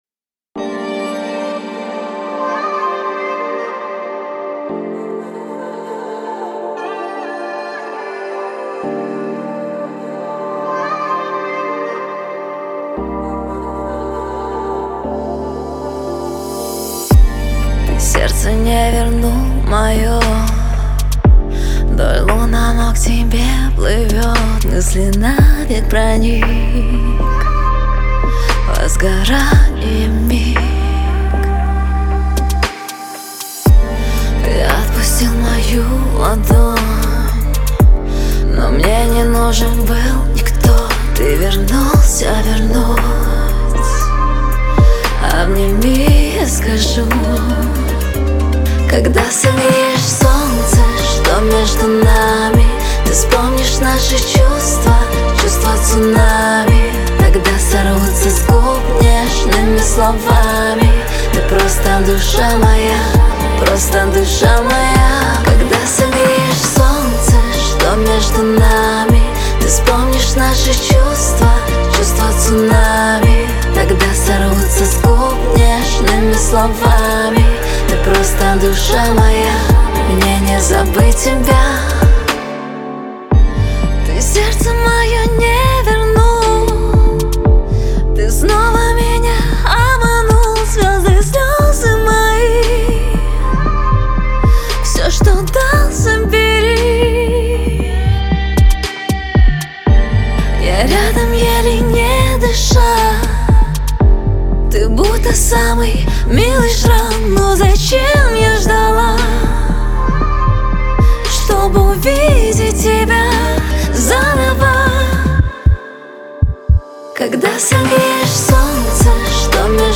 динамичная поп-песня